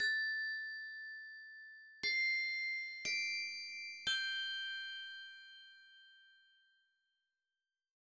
28 Bells PT1.wav